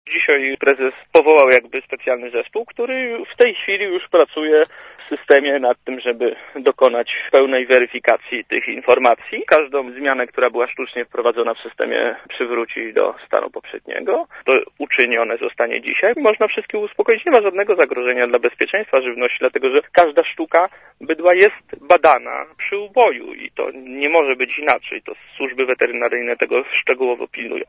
Żadnych danych dotyczących bydła nie zniszczono - zapewnił Radio ZET minister rolnictwa Wojciech Olejniczak.
Posłuchaj komentarza Wojciecha Olejniczaka